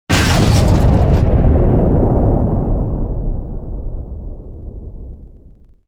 YouHit3.wav